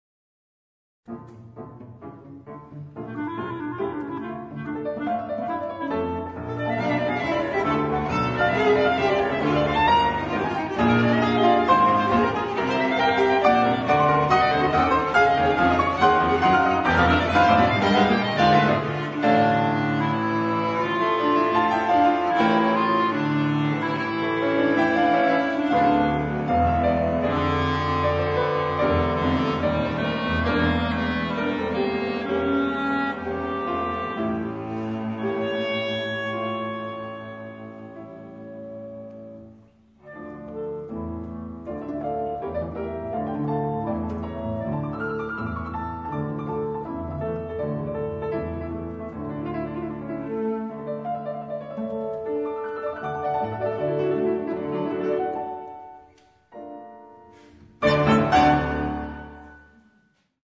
Allegro moderato
Trio pour clarinette piano violoncelle